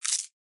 crisp_nom.mp3